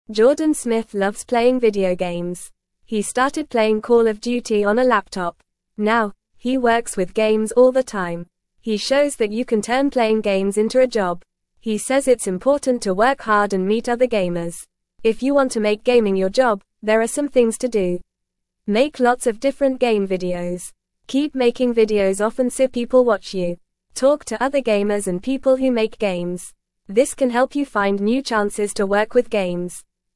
Fast
English-Newsroom-Beginner-FAST-Reading-Playing-Games-Can-Be-a-Job.mp3